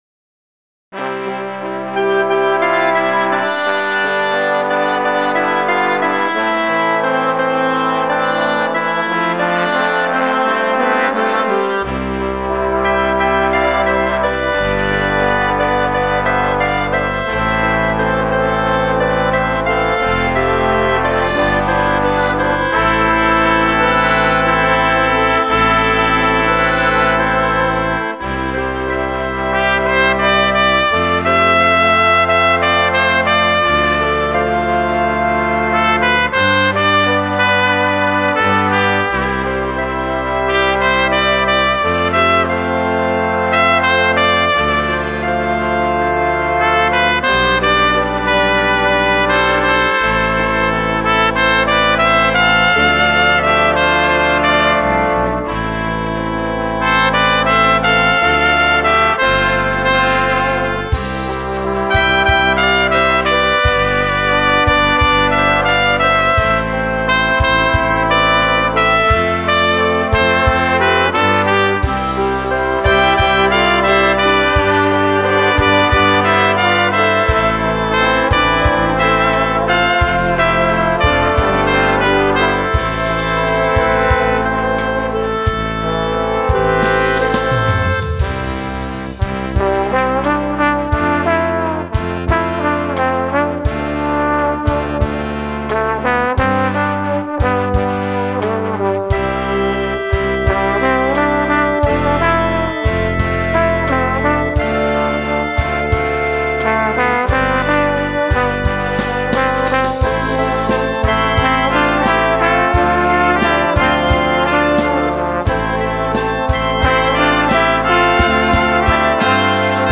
Dychová hudba
Inštrumentalne
Slow , Sólovky trumpeta